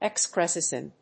音節ex・cres・cence 発音記号・読み方
/ekskrésns(米国英語)/